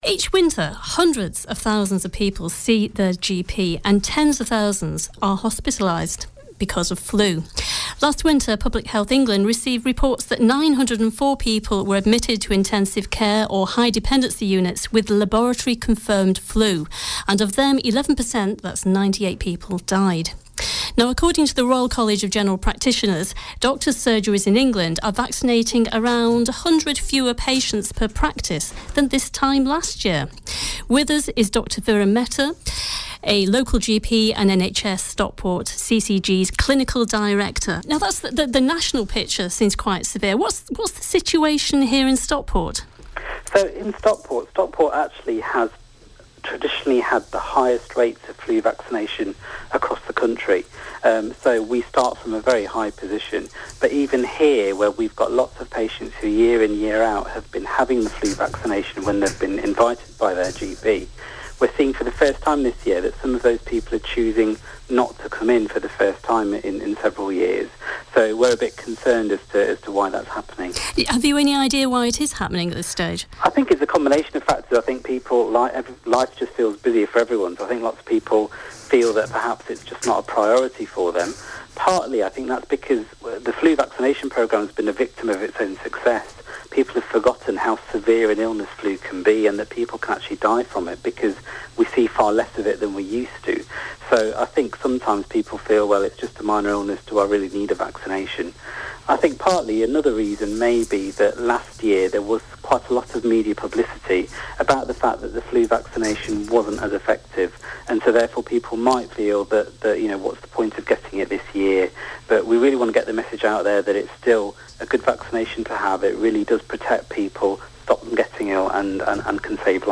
chat with a member of the local health community to get the info on how the NHS has seen a decline in people coming forward for flu jabs.